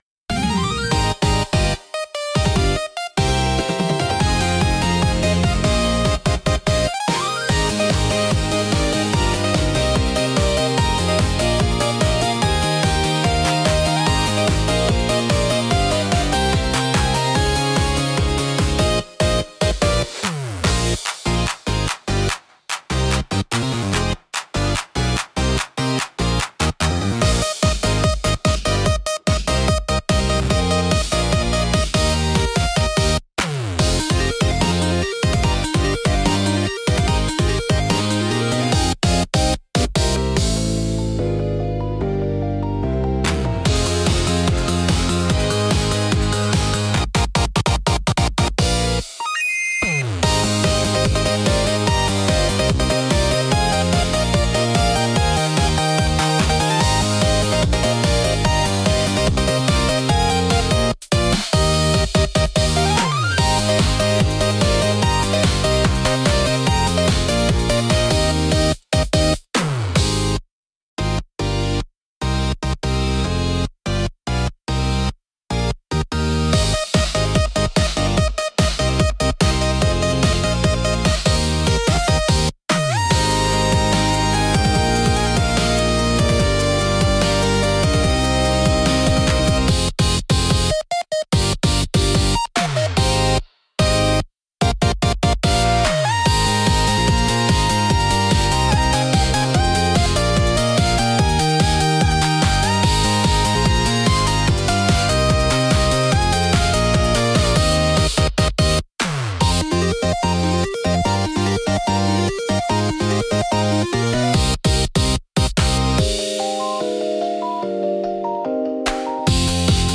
聴く人の気分を高め、緊張と興奮を引き立てるダイナミックなジャンルです。